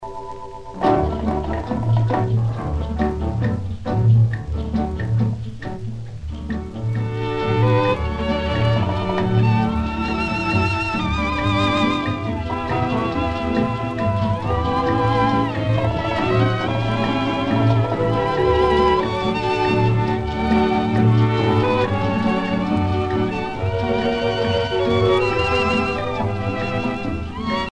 danse : rumba
Pièce musicale éditée